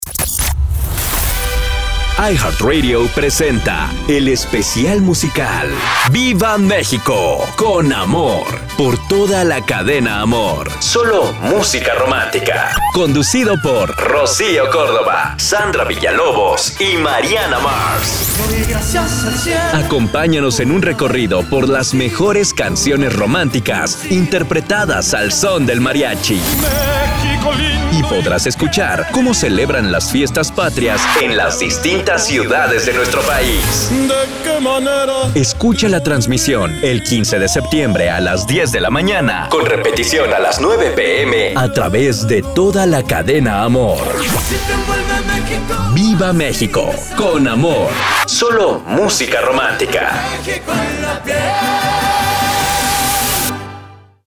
AMOR. Sólo Música Romántica